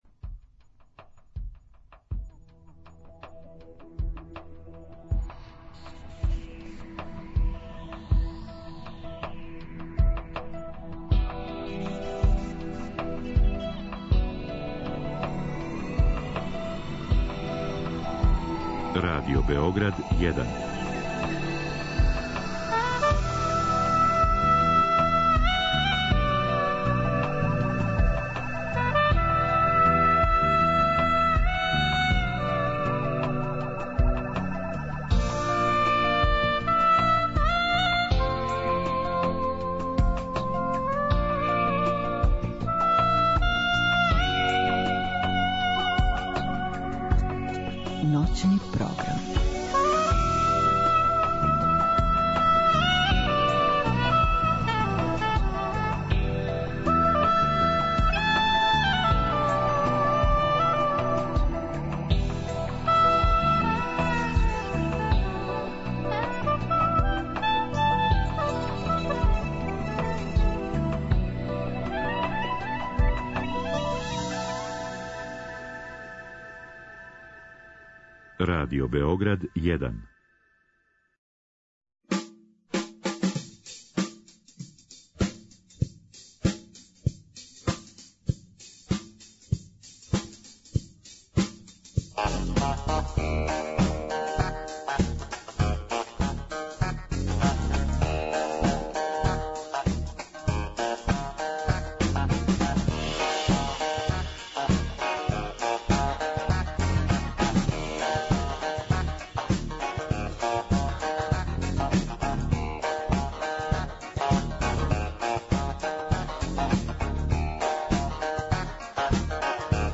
Телефонски разговор